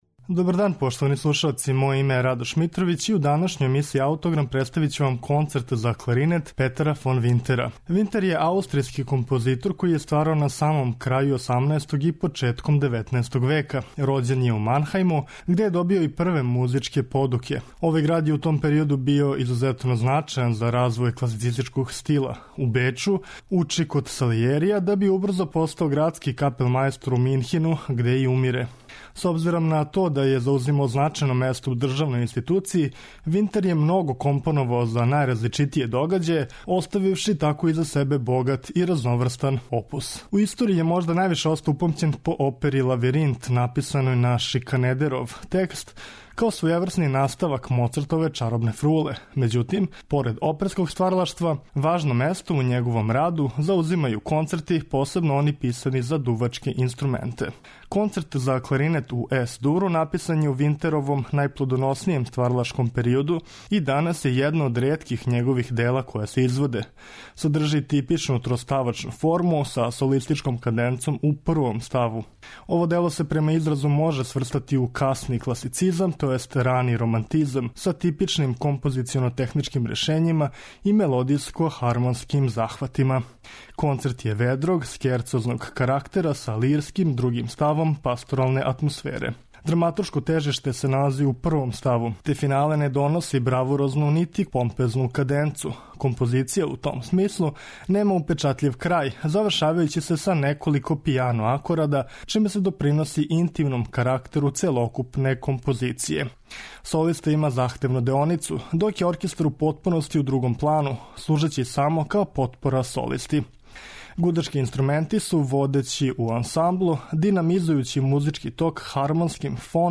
Садржи типичну троставачну форму, са солистичком каденцом у првом ставу. Ово дело се према изразу може сврстати у касни класицизам, тј. рани романтизам, са типичним композиционо техничким решењима и мелодијско хармонским захватима.